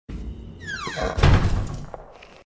DOOR SLAM.mp3
A door slammed hard, after a fight.
door_slam_jkl.ogg